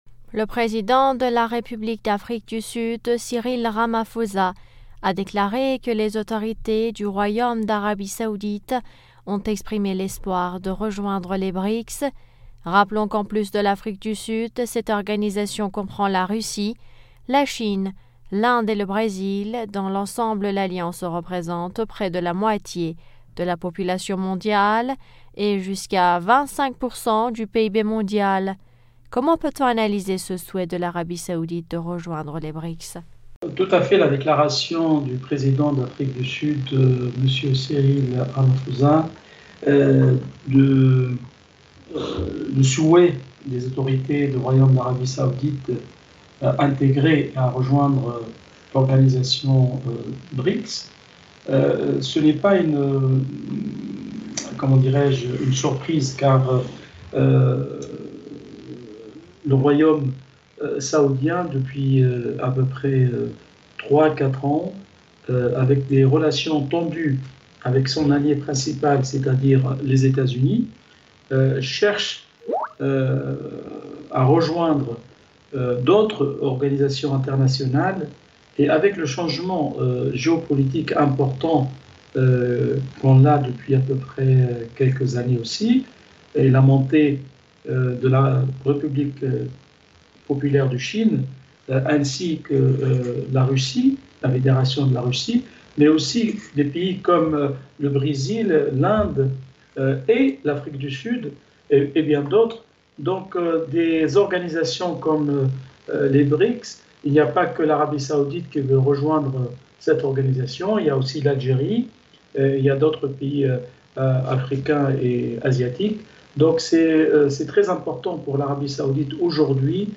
Mots clés Iran usa Arabie saoudie Pétrole interview Eléments connexes Trump : pourquoi veut-il faire croire ses mensonges sur l’Iran au monde ?